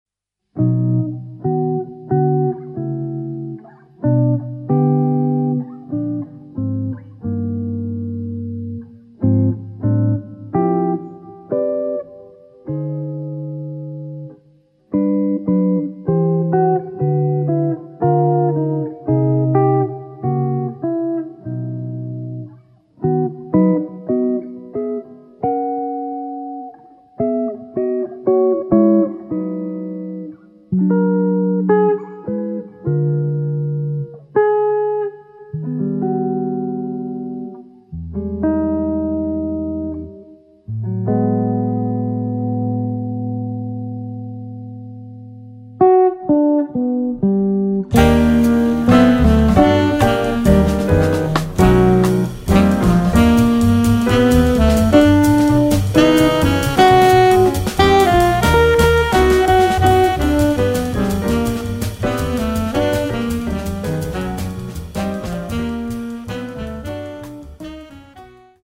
guitar
sax
piano
bass
drums